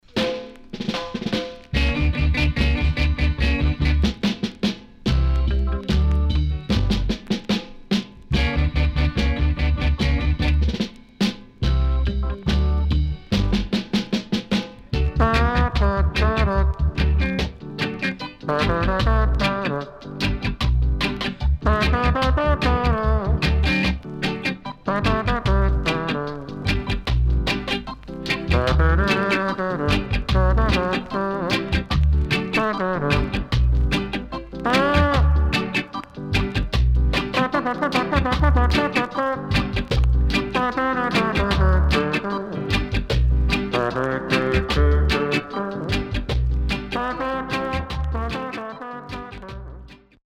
71年 Nice Vocal & Trombone Inst
SIDE A:序盤プチパチノイズありますが落ち着きます。